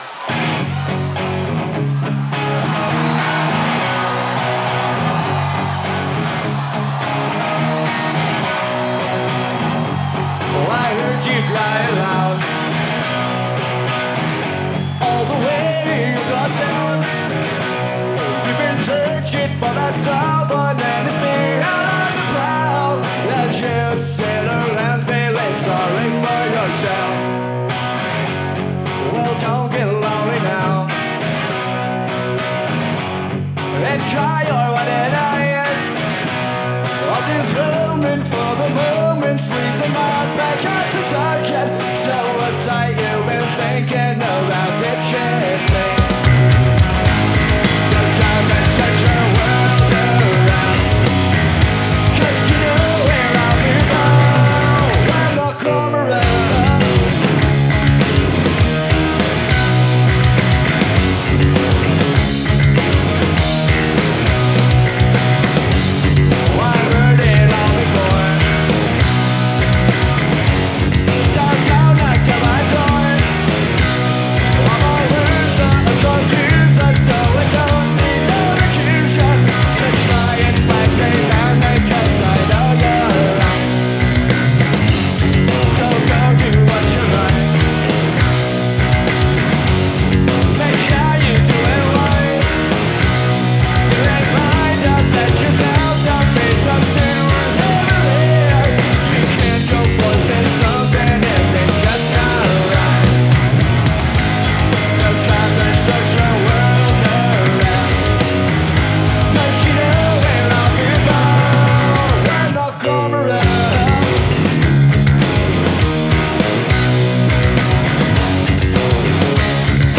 Live at Woodstock 1994